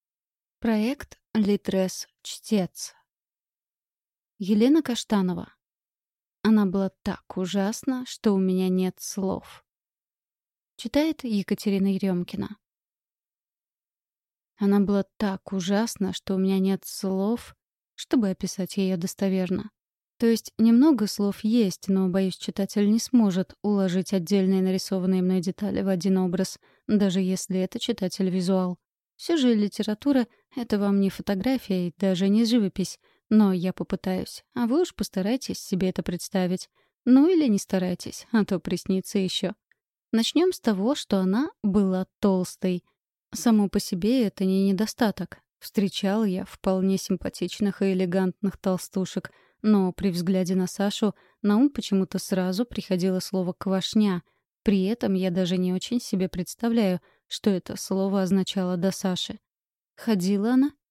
Аудиокнига Она была так ужасна, что у меня нет слов…